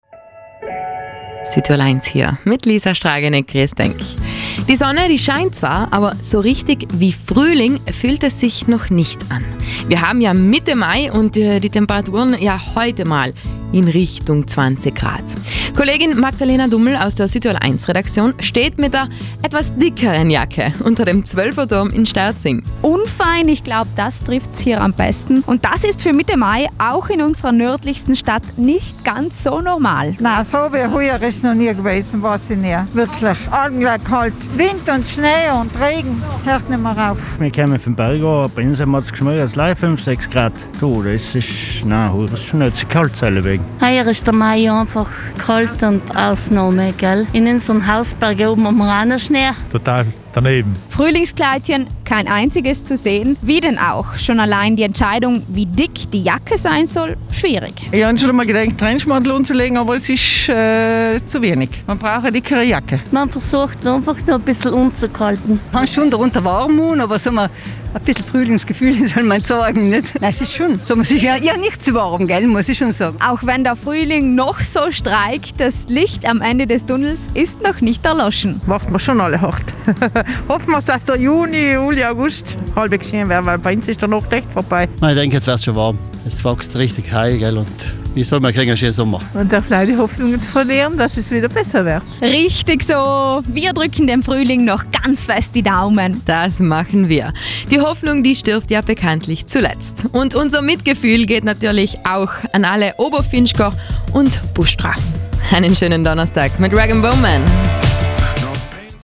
hat sich heute bei den Sterzingern umgehört, wie hart sie auf den Frühling warten.